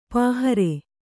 ♪ pāhare